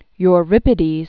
Eu·rip·i·des
(y-rĭpĭ-dēz) 480?-406 BC.